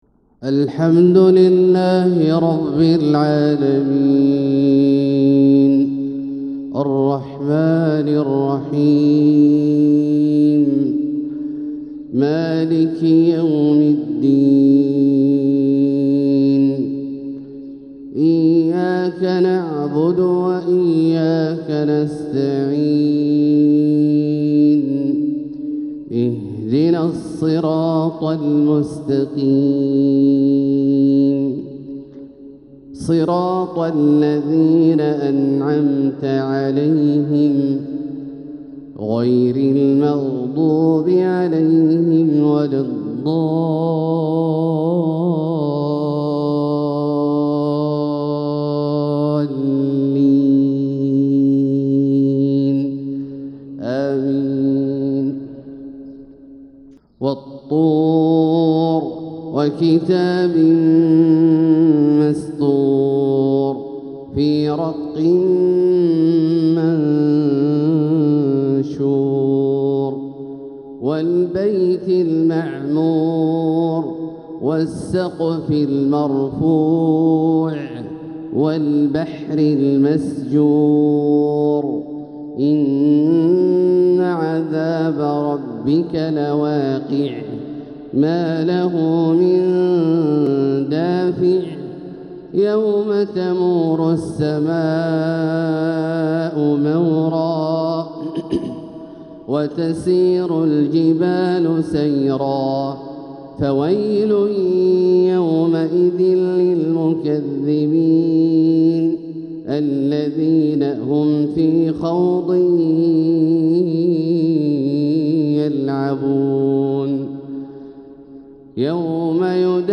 تلاوة لسورة الطور وآخر سورة النبأ | فجر الثلاثاء 3-5-1446هـ > ١٤٤٦ هـ > الفروض - تلاوات عبدالله الجهني